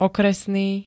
Zvukové nahrávky niektorých slov
4rke-okresny.ogg